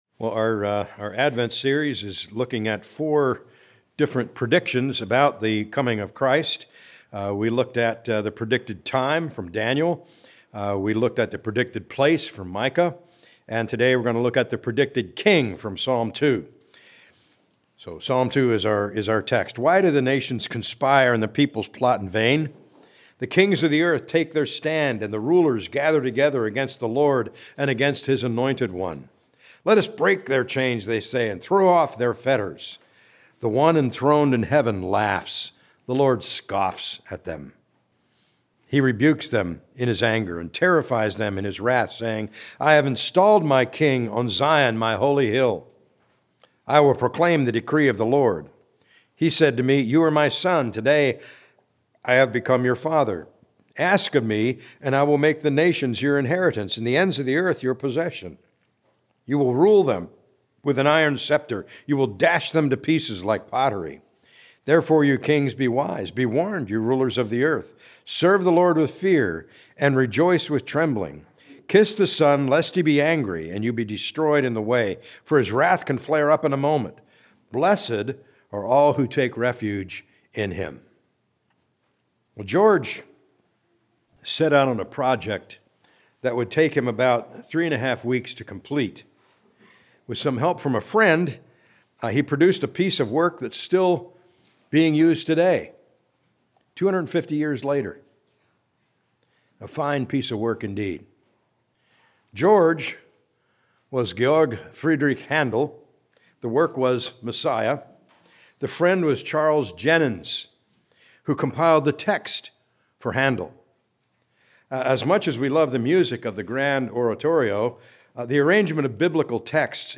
A message from the series "Advent 2024."